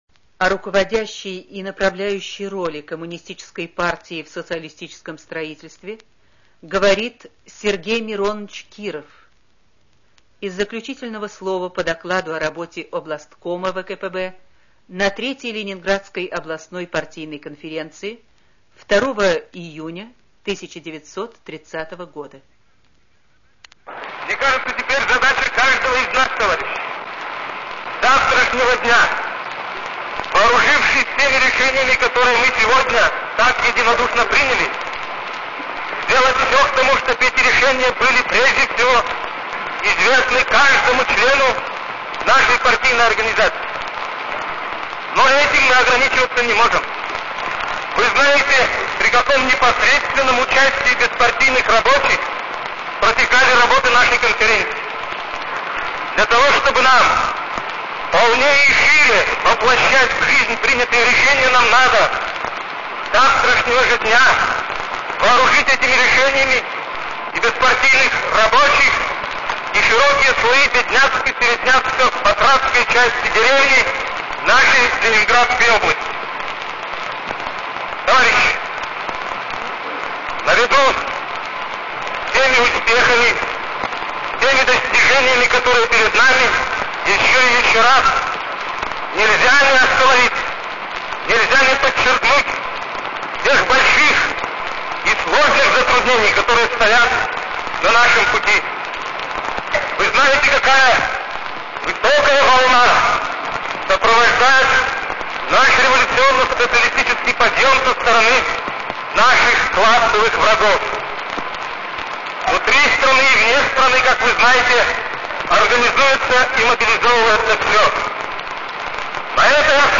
Речи